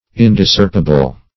Search Result for " indiscerpible" : The Collaborative International Dictionary of English v.0.48: Indiscerpible \In`dis*cerp"i*ble\, Indiscerptible \In`dis*cerp"ti*ble\, a. Not discerpible; inseparable.